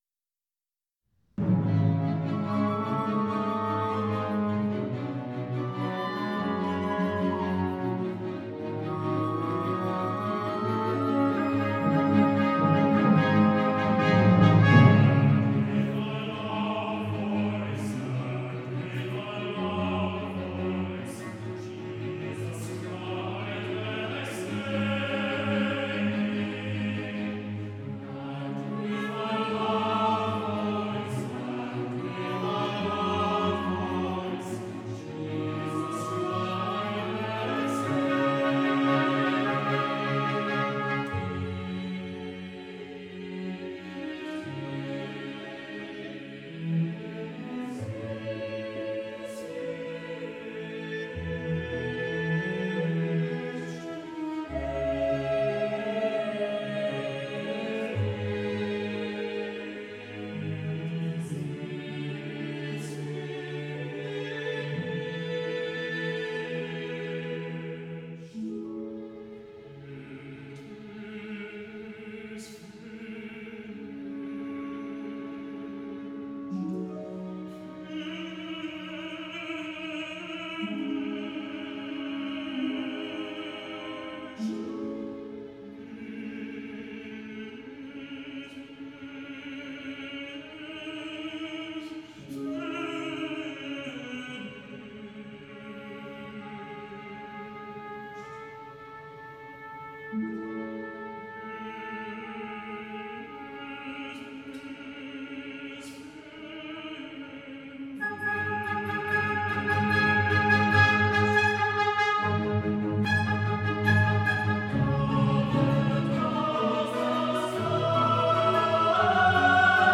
Genere: Choral.